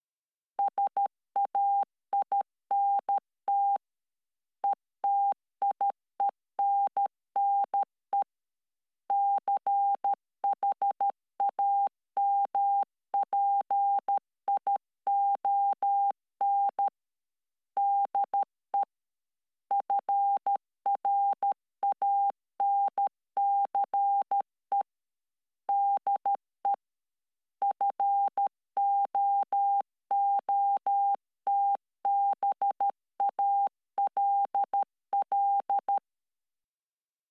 code-morse.mp3